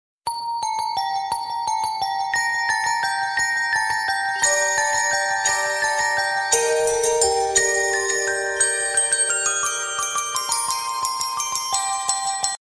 Little_Alert_Sound.mp3